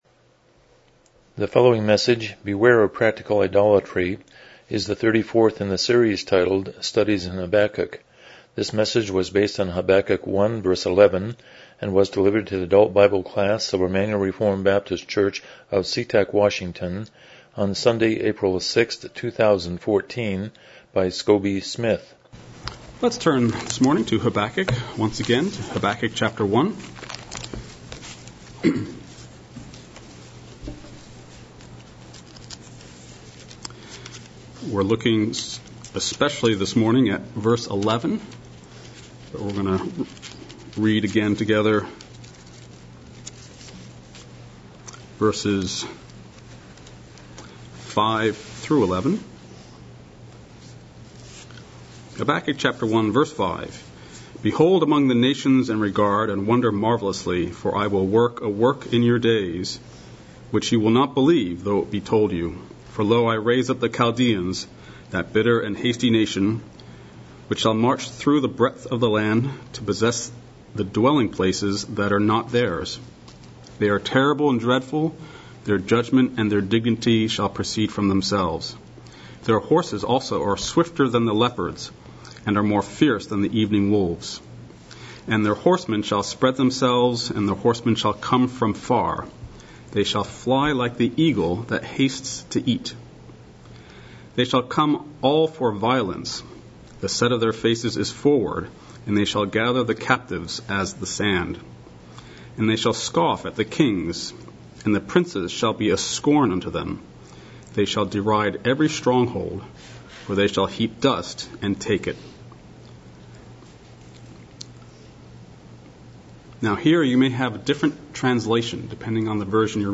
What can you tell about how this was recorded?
Habakkuk 1:11 Service Type: Sunday School « The Natural